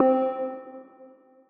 Pluck - Kirby.wav